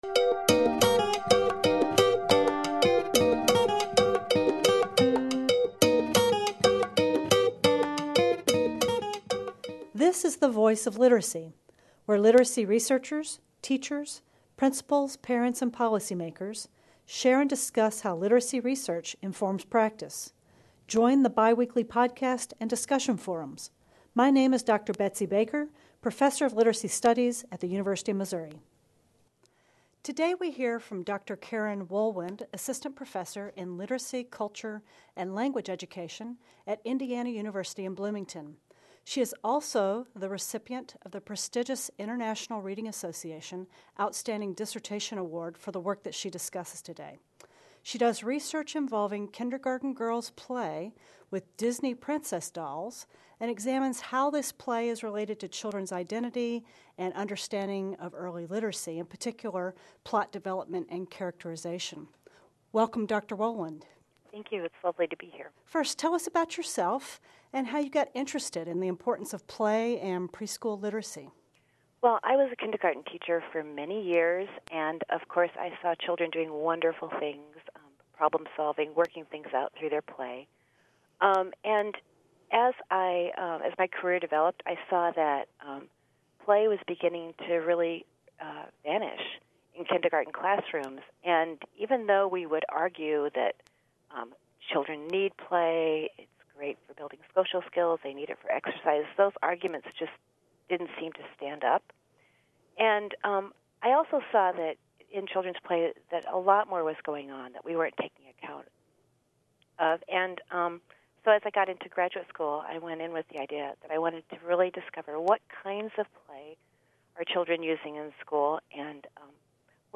interview
interviews